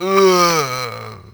c_zombim3_atk1.wav